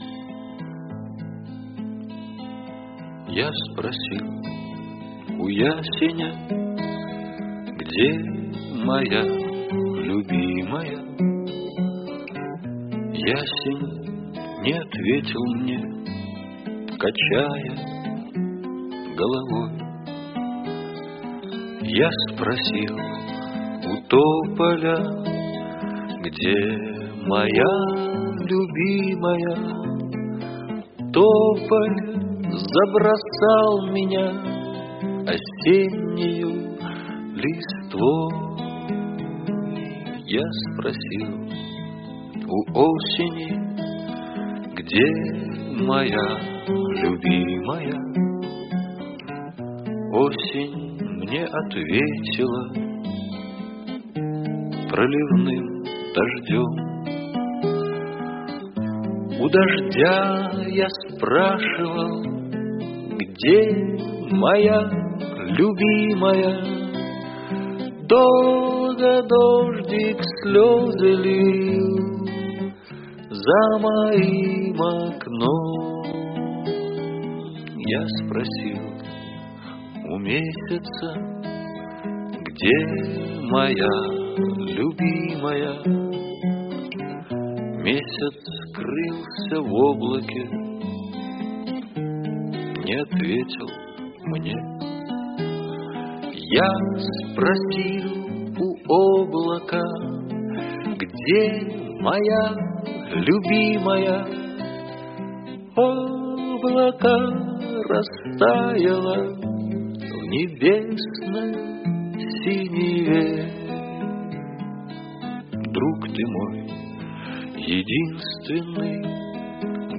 грустная песня о любви.